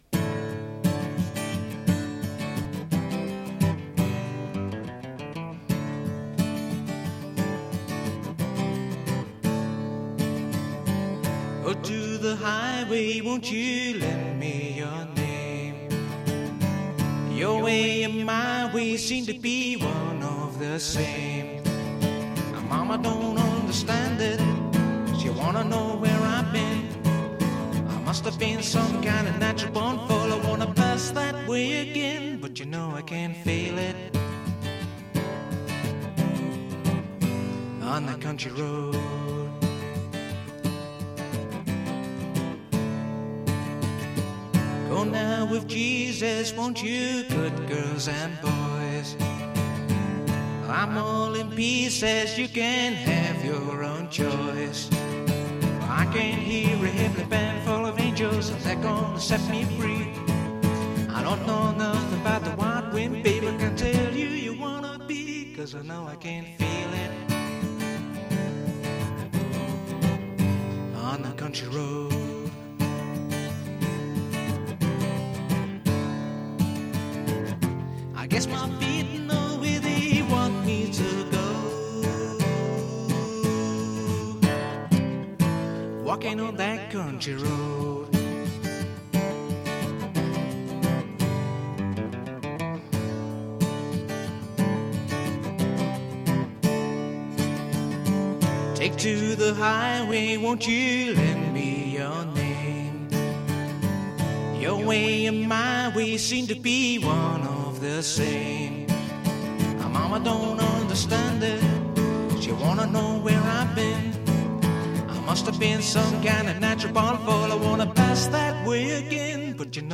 A couple of solos